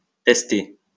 wymowa:
IPA[tɛs.te]